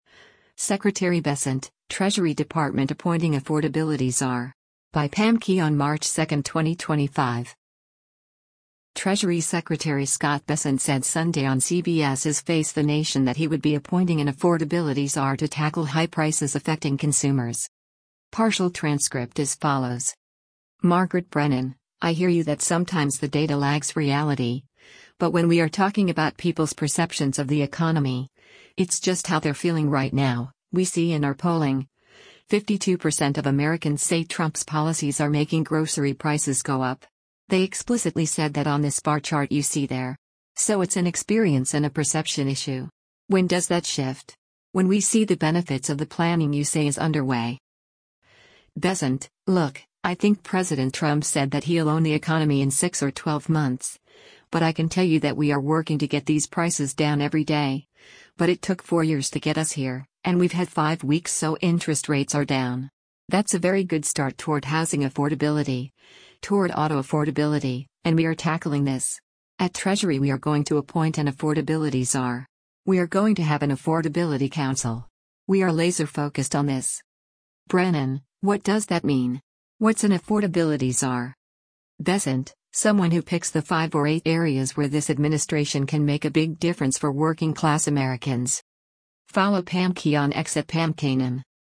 Treasury Secretary Scott Bessent said Sunday on CBS’s “Face the Nation” that he would be appointing an “affordability czar” to tackle high prices affecting consumers.